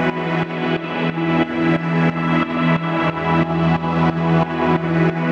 GnS_Pad-dbx1:8_90-E.wav